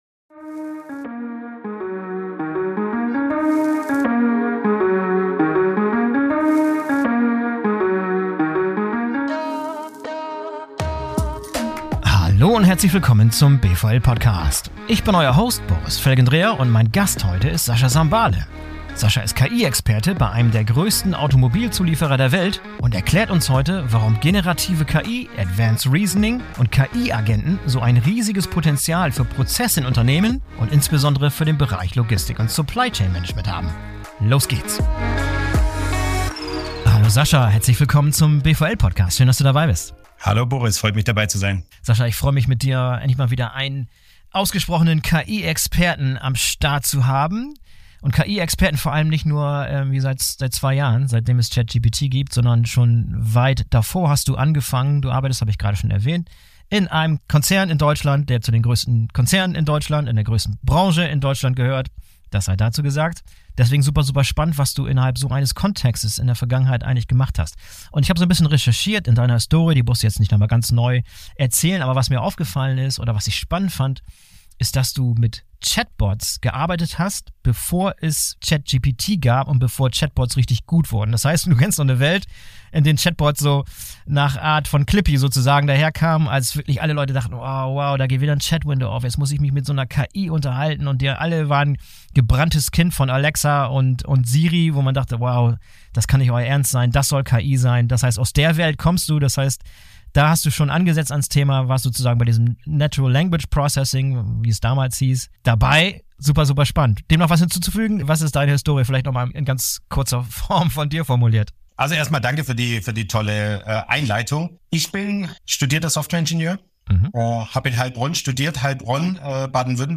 In einem ausführlichen, persönlichen Gespräch, das einen echten Blick hinter die Kulissen der Unternehmen, der Personen und deren Ideen und Herausforderungen ermöglicht.